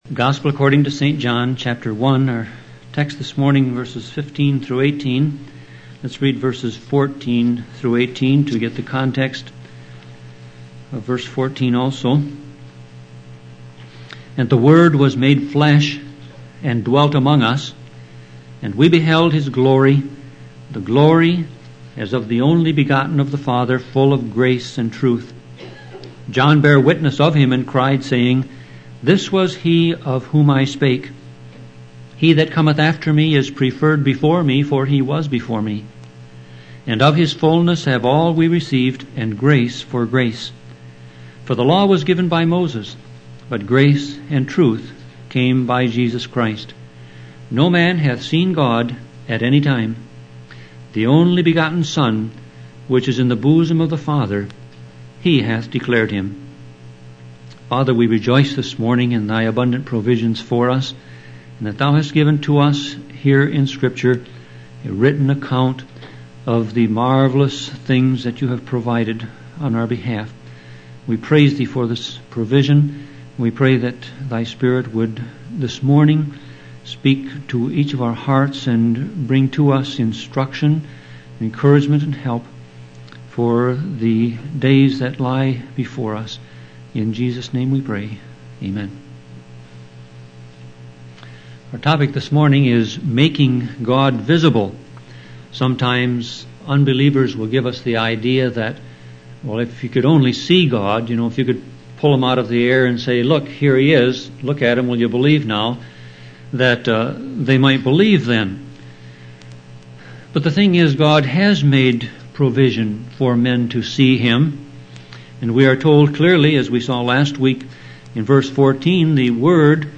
Sermon Audio Passage: John 1:15-18 Service Type